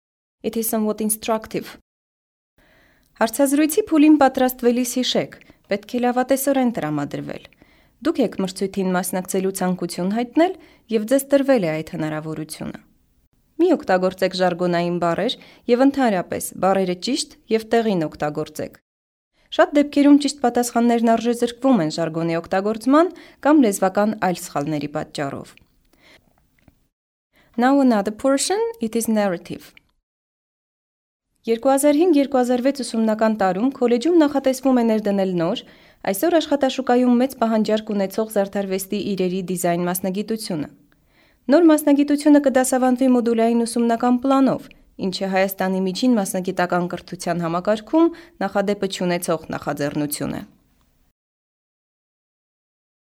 Armenian fewmale voice
Sprechprobe: Sonstiges (Muttersprache):